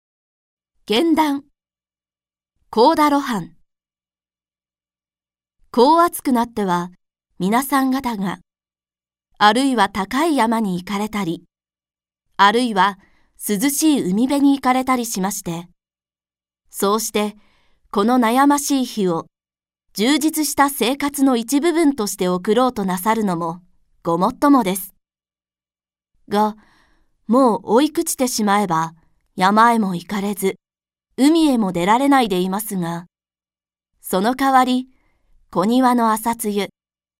• 弊社録音スタジオ
朗読街道は作品の価値を損なうことなくノーカットで朗読しています。